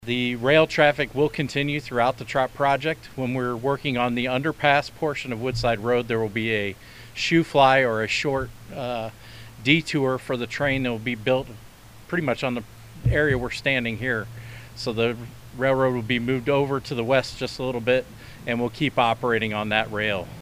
Local officials conducted a groundbreaking ceremony on Monday morning at Woodside and Iron Bridge roads.